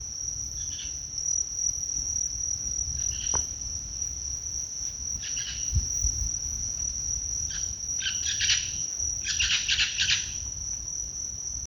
Loro Maitaca (Pionus maximiliani)
Nombre en inglés: Scaly-headed Parrot
Localización detallada: Misión de Loreto
Condición: Silvestre
Certeza: Fotografiada, Vocalización Grabada
Loro-Maitaca--1-_1.mp3